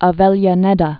(ä-vĕlyä-nĕdä, ä-vĕyä-, ä-vĕzhä-)